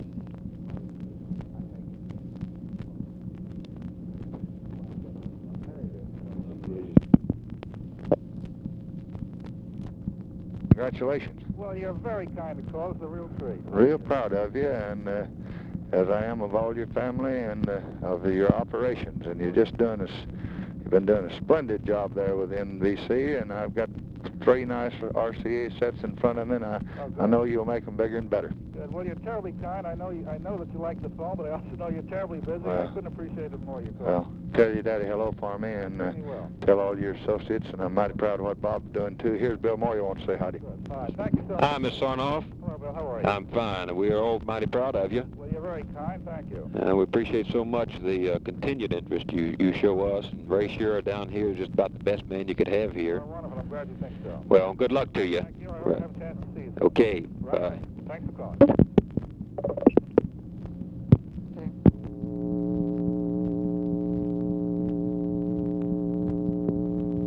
Conversation with ROBERT SARNOFF and BILL MOYERS, September 3, 1965
Secret White House Tapes